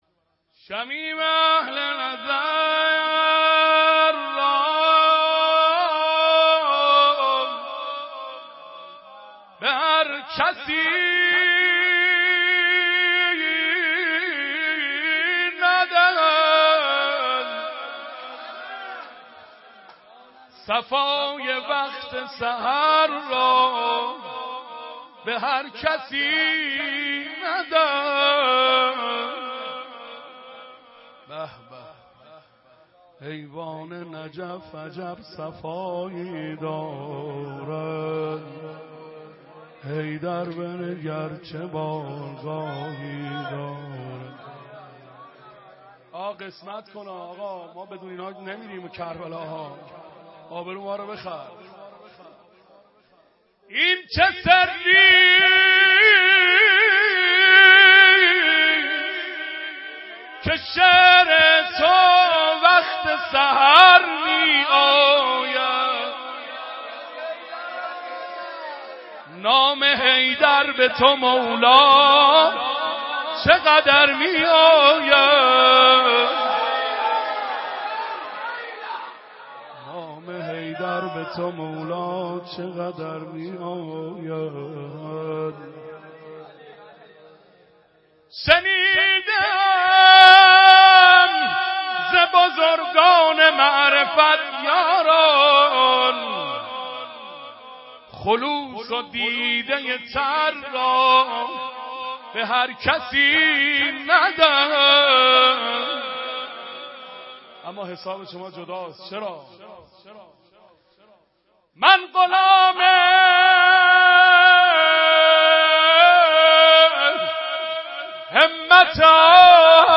شب اربعین حسینی